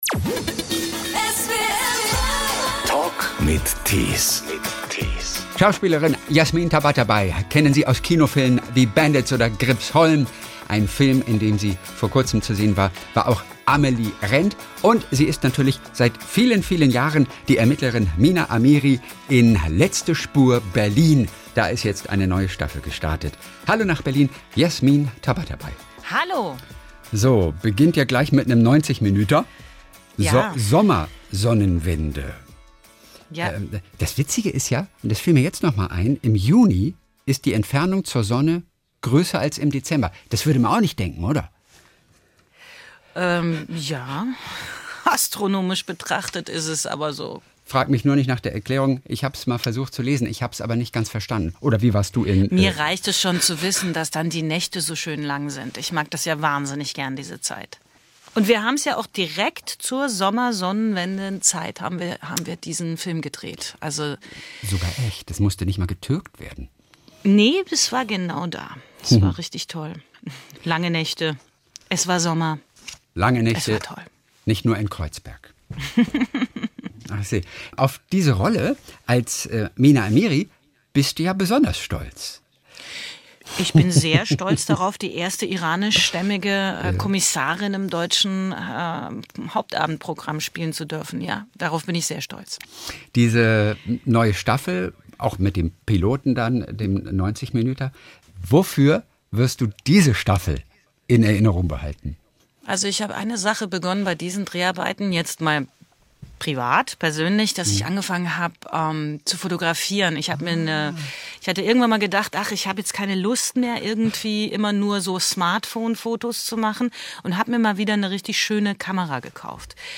Der Talk in SWR 3